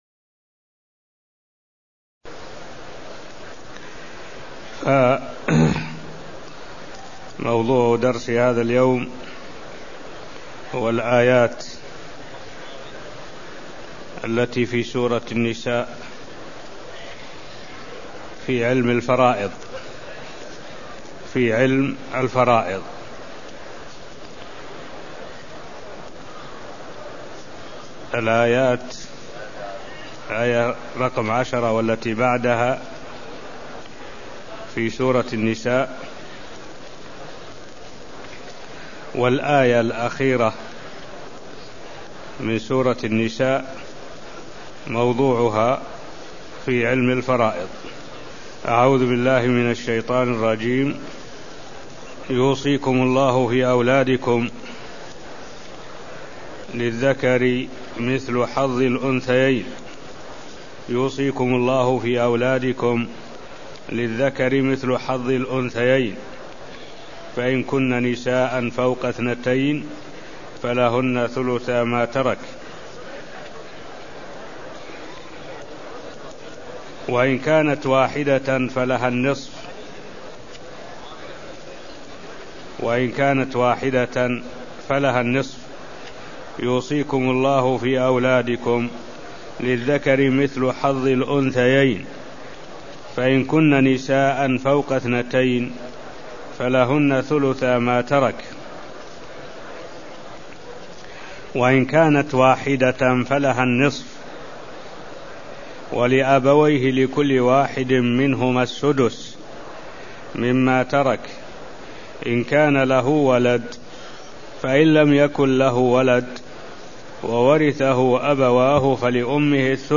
المكان: المسجد النبوي الشيخ: معالي الشيخ الدكتور صالح بن عبد الله العبود معالي الشيخ الدكتور صالح بن عبد الله العبود سورة النساء 11 (0209)